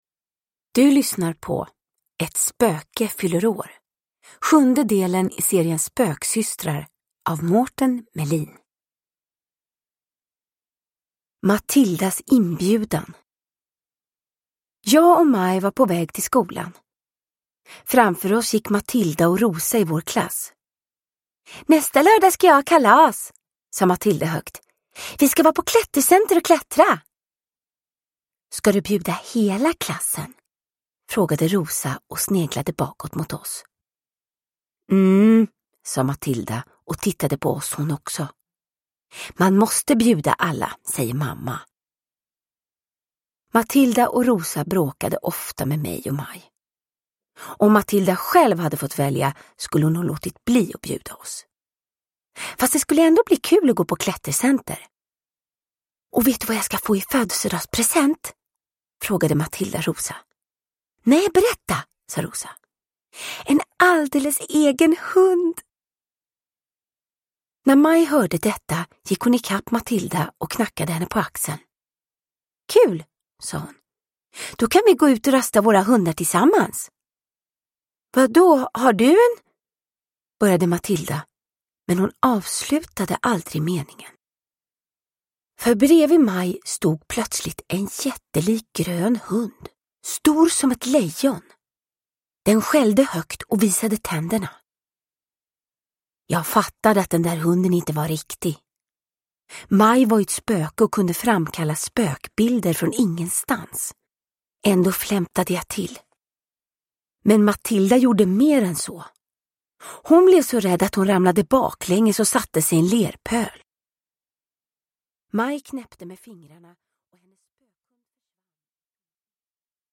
Ett spöke fyller år – Ljudbok – Laddas ner
Uppläsare: Vanna Rosenberg